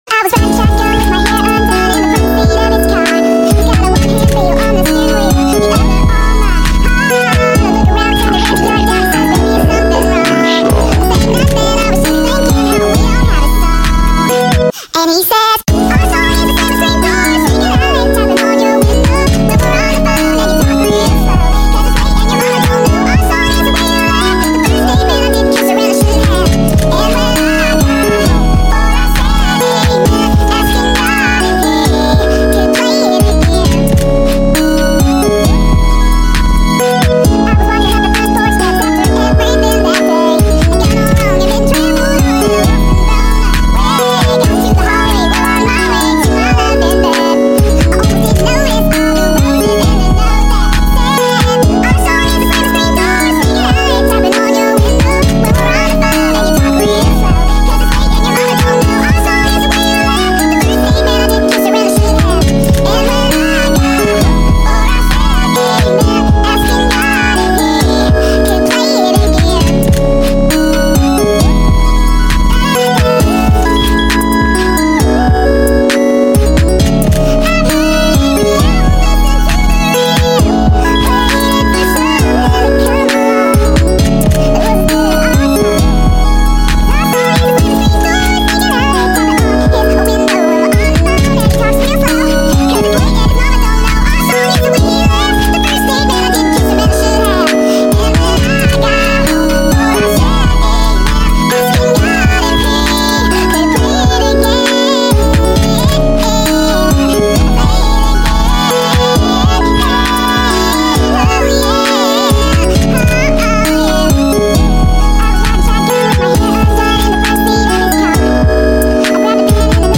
Pluggnb Remix